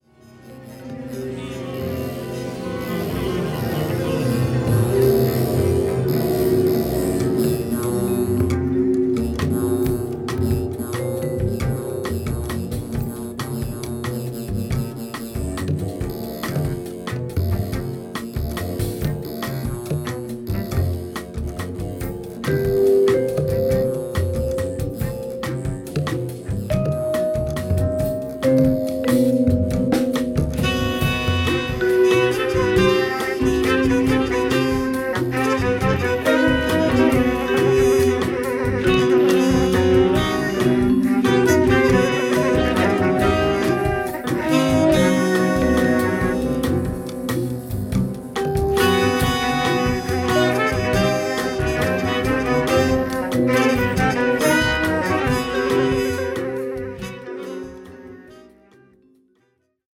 JAZZ / JAZZ FUNK / FUSION
エチオピアの音楽にジャズやラテン音楽などを融合させた「エチオ・ジャズ」